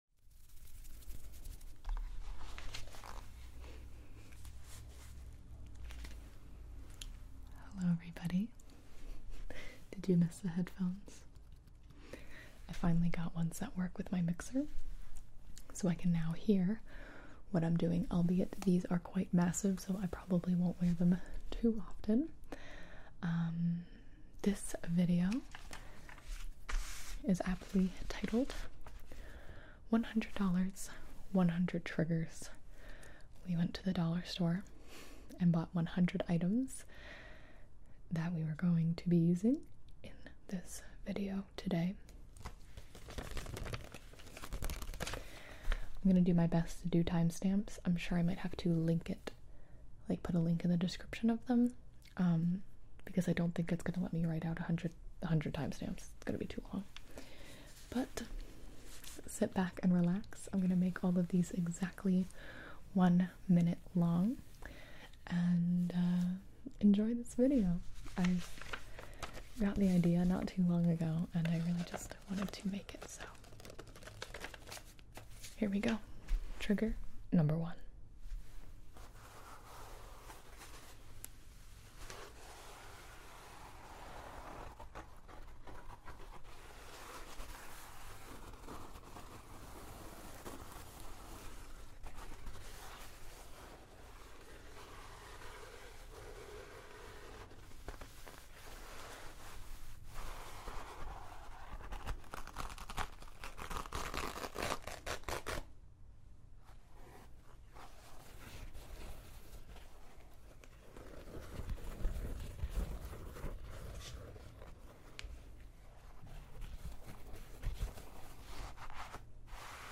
Get ready to unwind and drift off with over 1.5 hours of soothing ASMR triggers, all sourced from everyday $100 store treasures. This mega ASMR session features 100 unique sounds and textures designed to relax your mind, ease your stress, and guide you gently into sleep. Perfect for ASMR lovers and anyone in need of deep relaxation, this episode is your ultimate sound escape—no fancy equipment needed, just simple, satisfying triggers that work wonders.